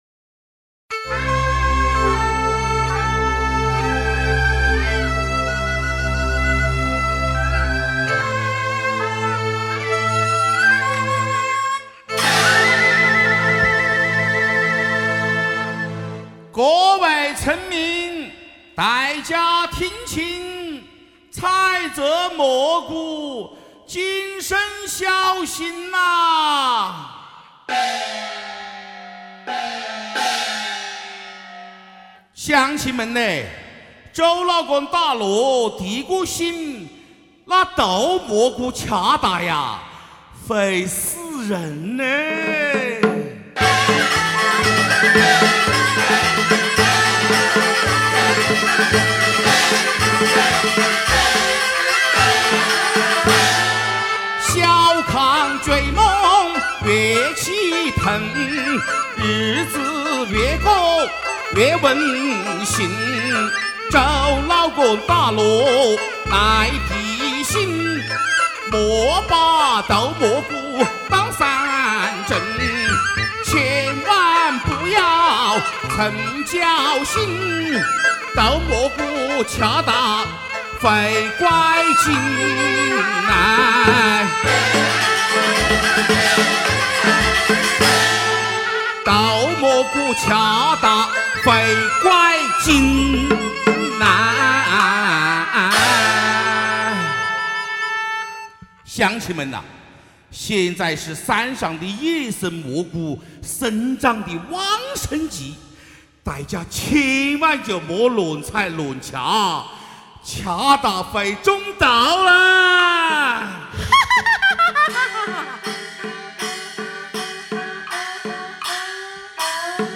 花鼓小戏《毒蘑菇，惹不起》